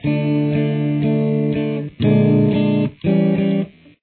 Dm : ( played four times standard )
Bb7: (played twice )
A7: ( played twice )